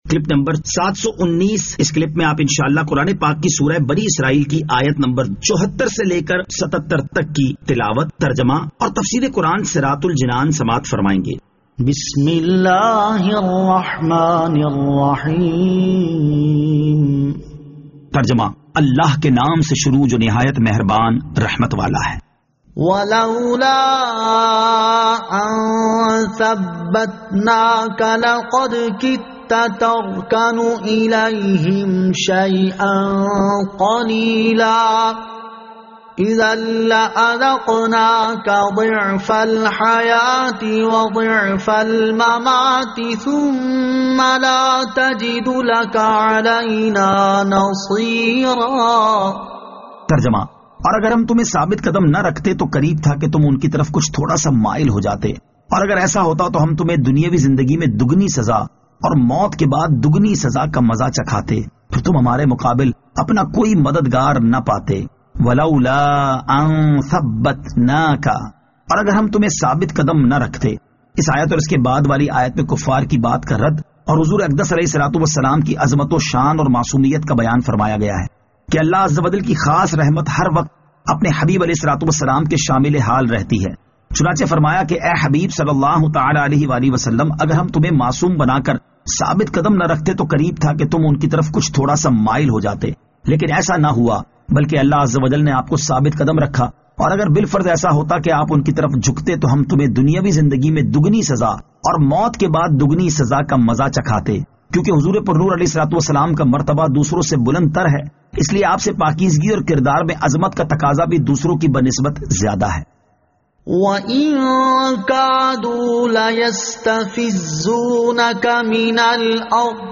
Surah Al-Isra Ayat 74 To 77 Tilawat , Tarjama , Tafseer